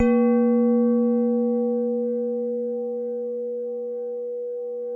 WHINE  A#1-R.wav